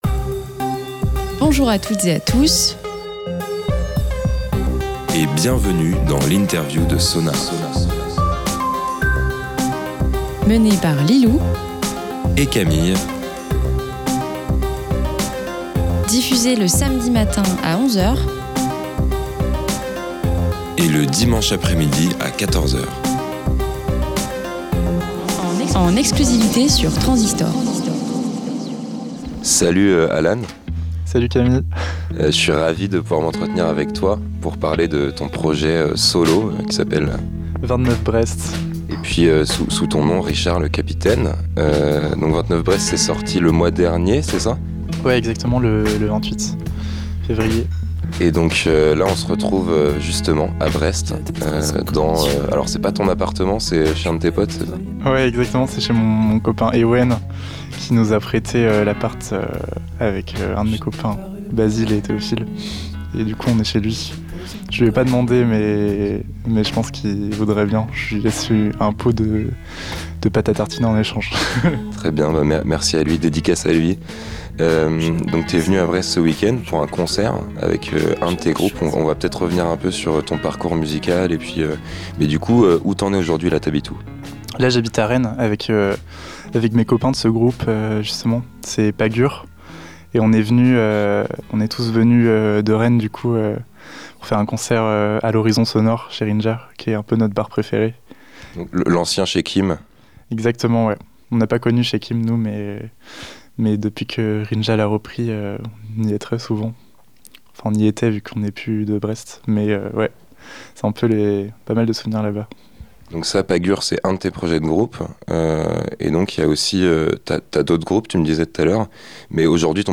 SONAR x INTERVIEW "29 Brest"
Vous pourrez découvrir tout ça dans cet entretien pour Transistoc'h.